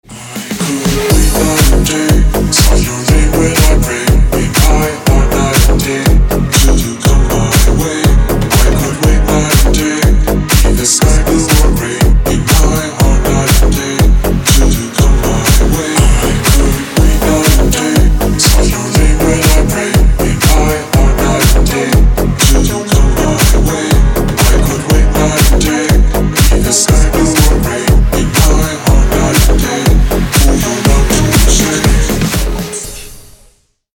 • Качество: 256, Stereo
мужской вокал
deep house
Electronic
club
house
vocal